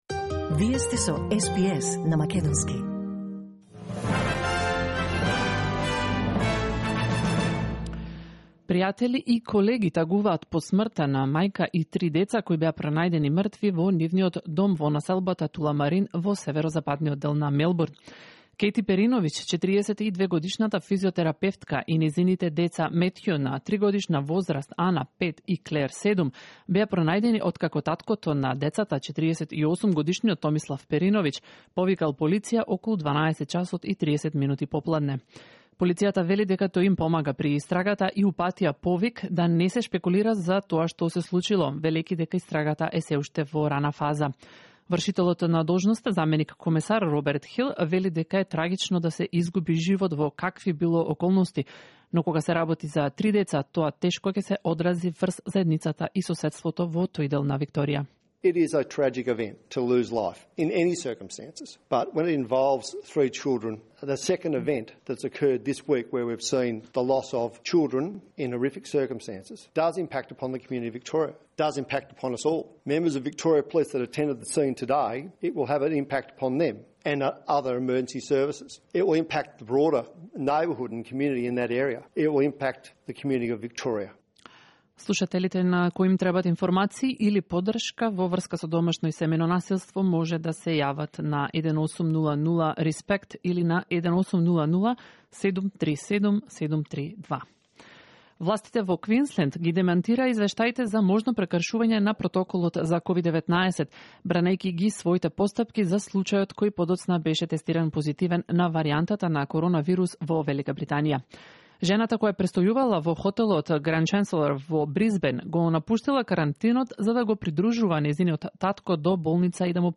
SBS News in Macedonian 15 January 2021